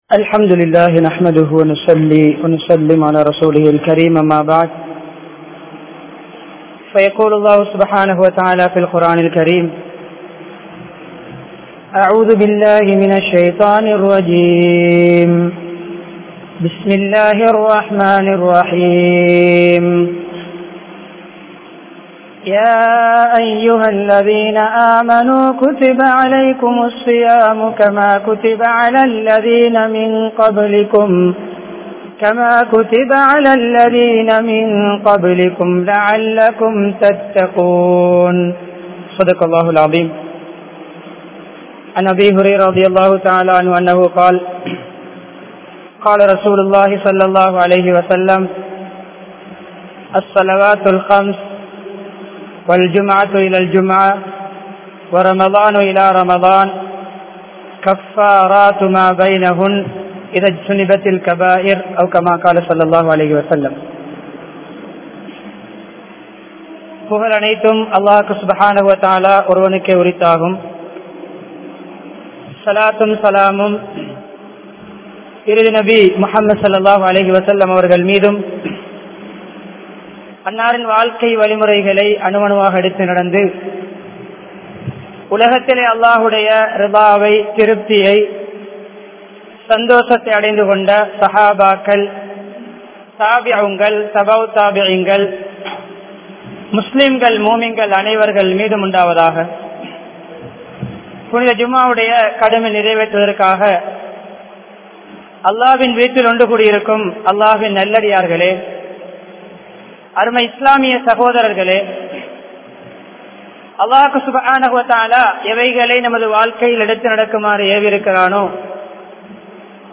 Ramalanai Sirappaaha Kalippoam (ரமழானை சிறப்பாக கழிப்போம்) | Audio Bayans | All Ceylon Muslim Youth Community | Addalaichenai